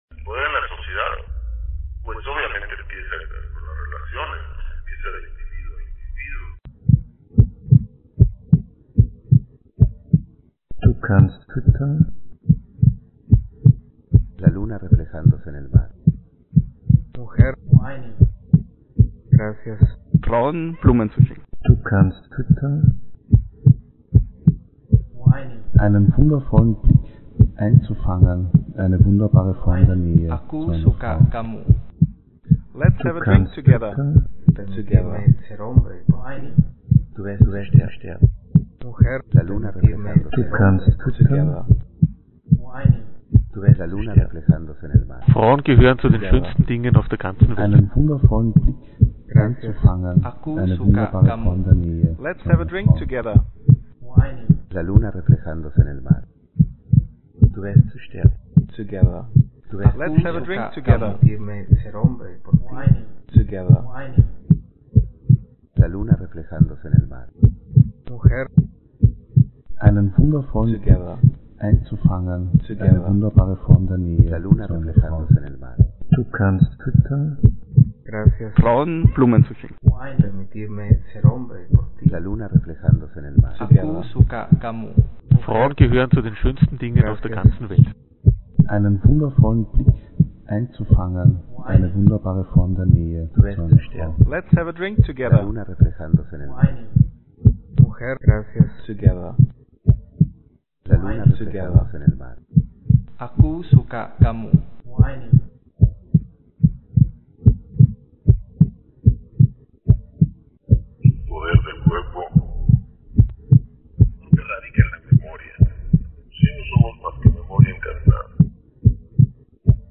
de unterot, las voces seductoras de los hombres/from unterot, seducing voices of men
men around where asked: what do you say to a girl you like?